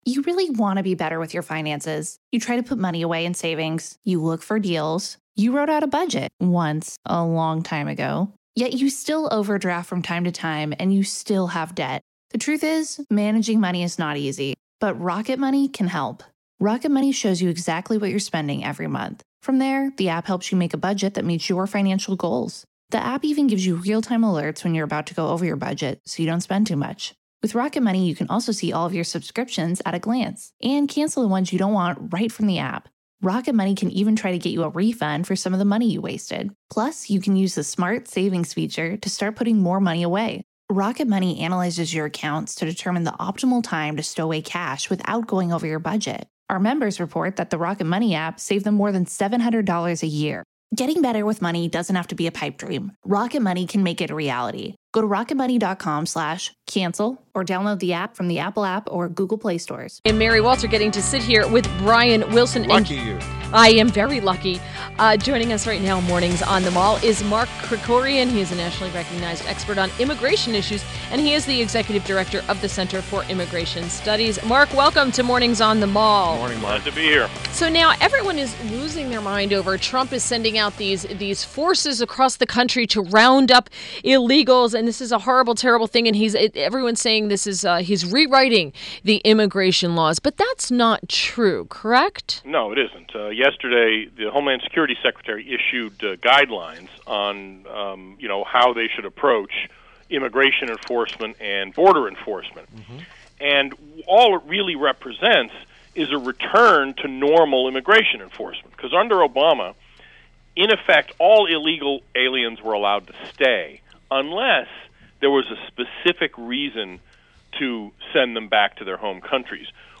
INTERVIEW — MARK KRIKORIAN — a nationally recognized expert on immigration issues and is the Executive Director of the Center for Immigration Studies (CIS)